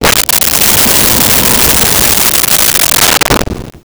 Wind Howl 03
Wind Howl 03.wav